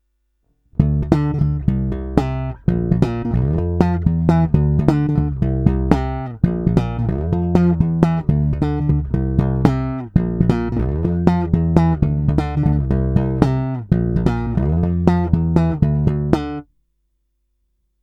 Není-li uvedeno jinak, jsou provedeny rovnou do zvukovky s plně otevřenou tónovou clonou a jen normalizovány, jinak bez dalších úprav.
Slap